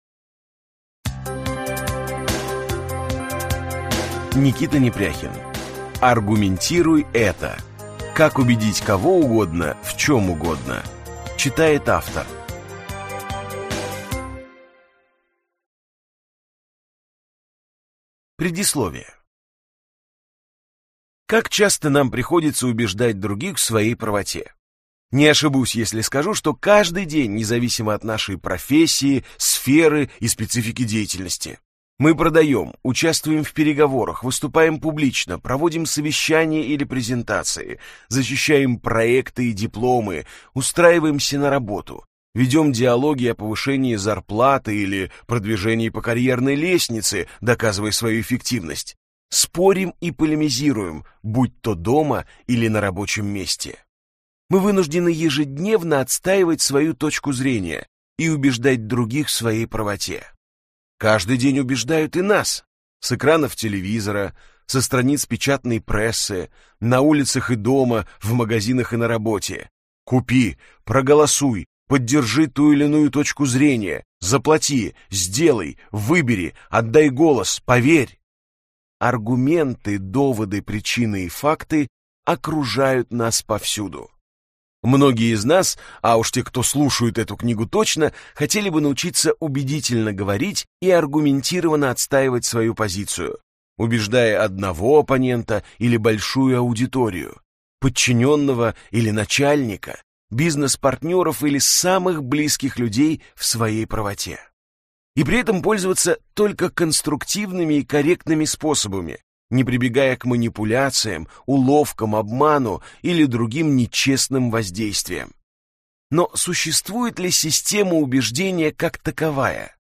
Аудиокнига Аргументируй это! Как убедить кого угодно в чем угодно | Библиотека аудиокниг